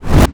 pgs/Assets/Audio/Magic_Spells/casting_charge_whoosh_buildup2.wav
A=PCM,F=96000,W=32,M=stereo
casting_charge_whoosh_buildup2.wav